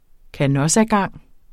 Udtale [ kaˈnʌsa- ]